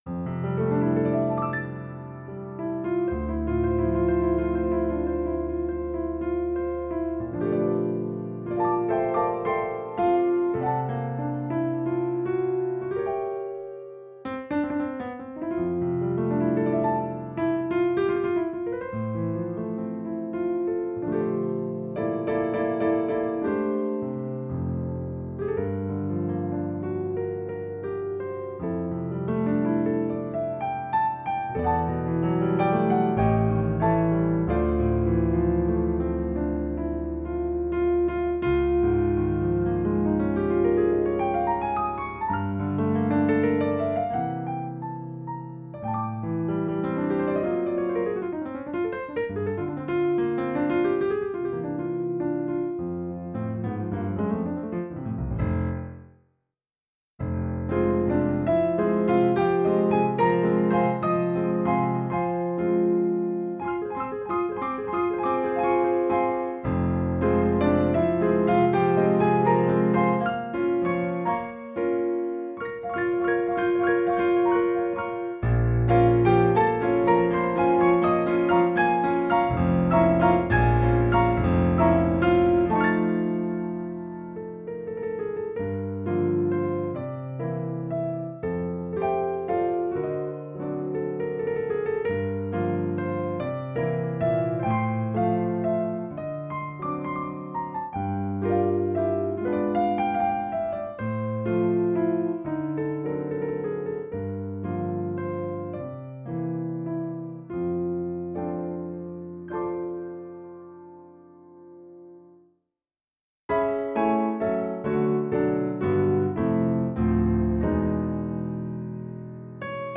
lk-Gem RP910 Fazioli.mp3